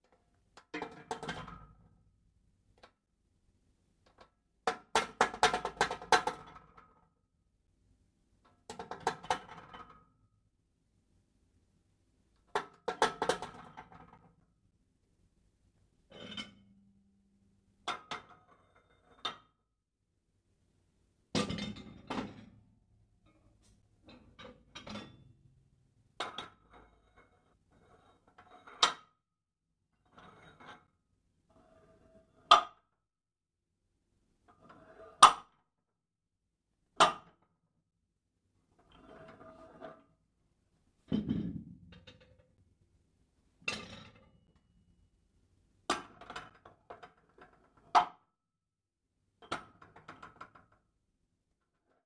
Звук спортсмена со штангой в зале